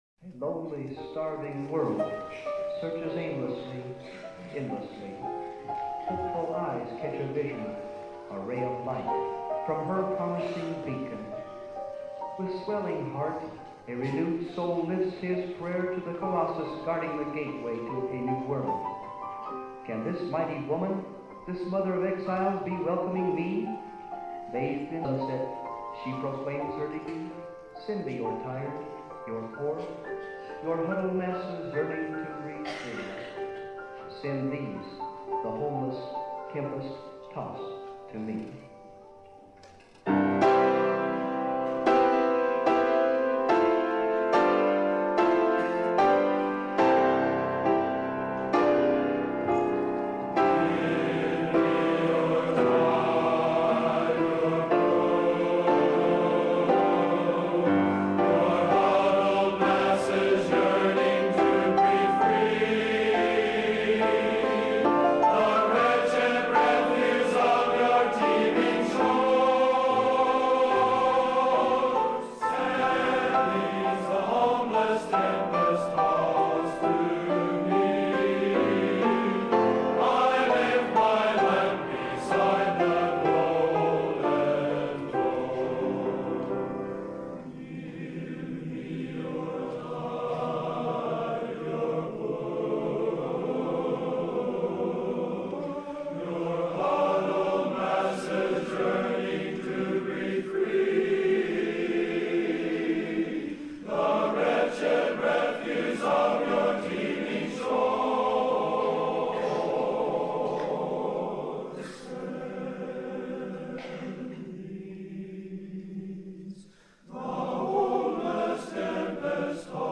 America, Our Heritage - BNC Plainsmen Spring Concert recorded 04 May 1967.